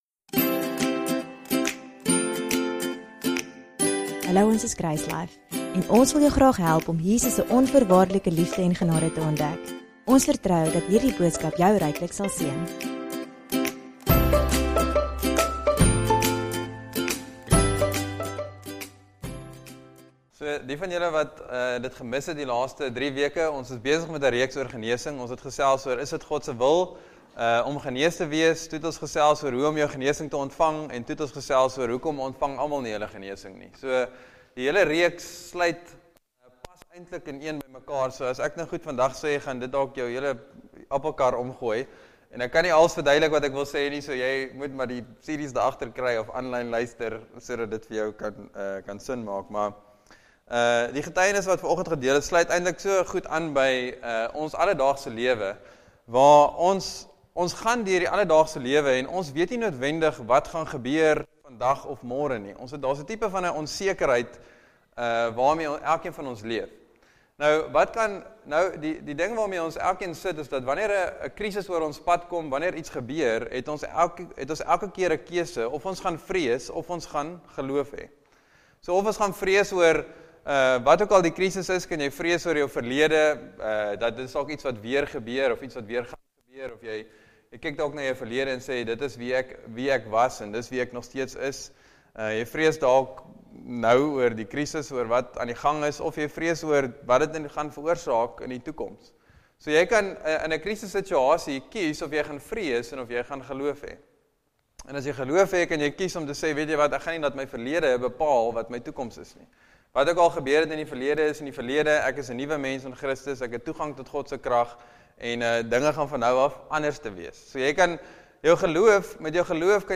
DOWNLOAD READ MORE Sermon Test Category